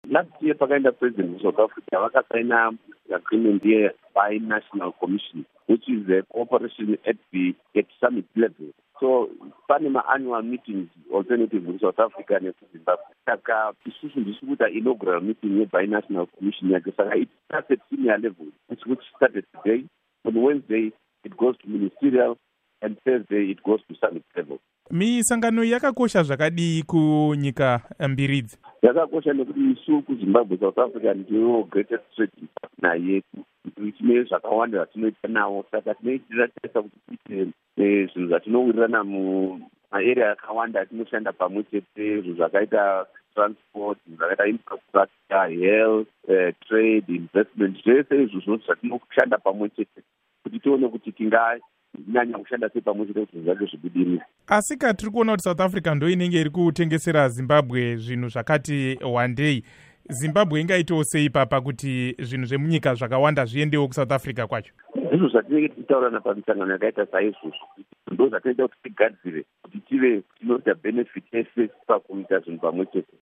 Hurukuro naVaMike Bimha